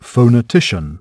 SOPRASEGMENTALI
Accento primario